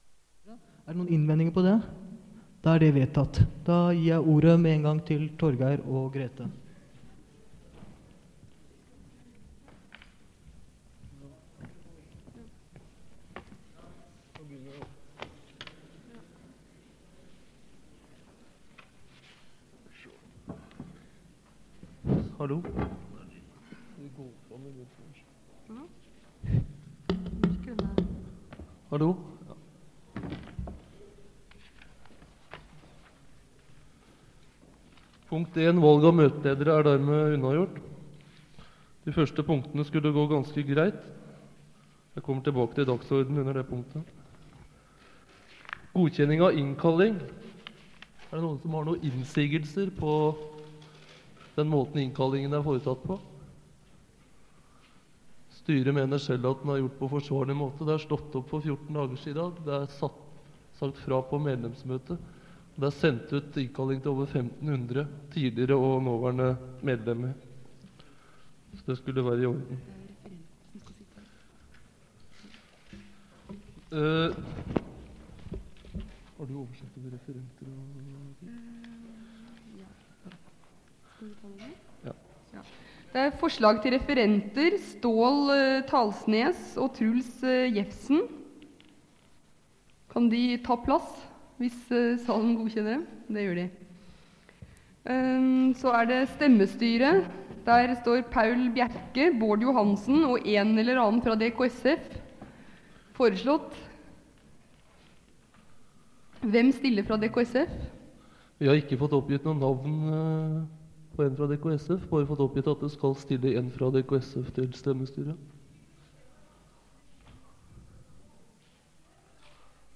Det Norske Studentersamfund, Generalforsamling, 27.11.1981 (fil 1-2:5)